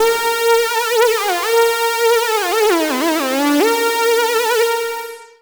TRILOGY RIFF.wav